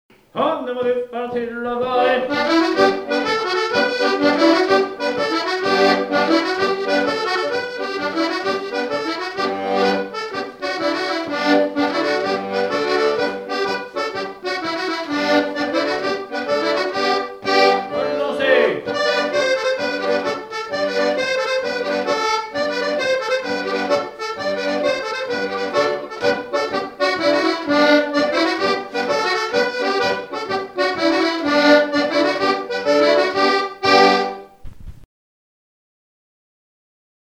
Quadrille - Avant-deux
Bournezeau
danse : quadrille : avant-deux
Pièce musicale inédite